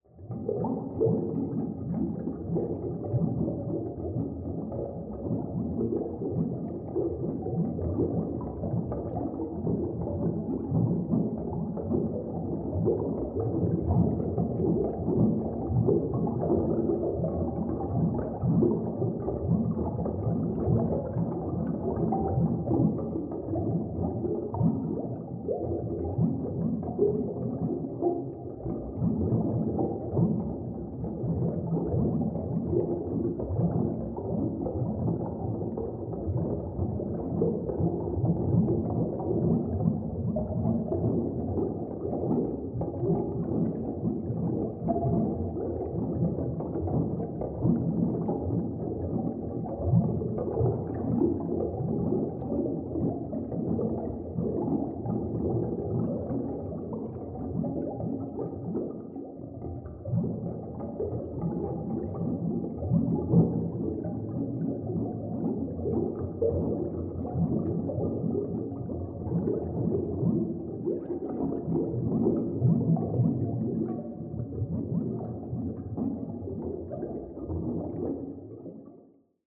Dive Deep - Bubbles 12.wav